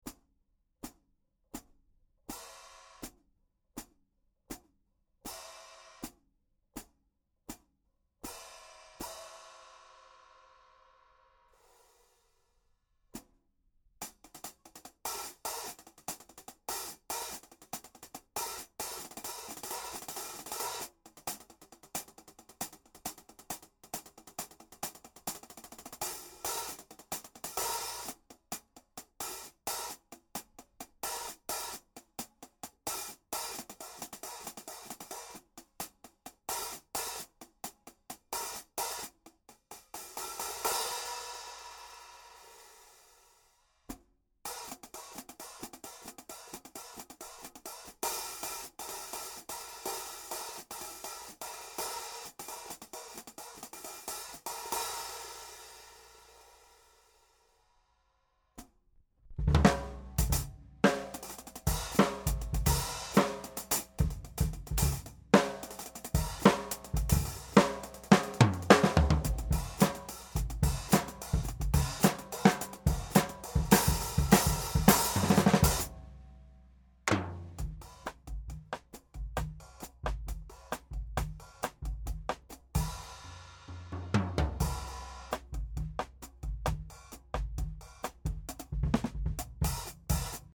14" Master Hi-Hat 852/942g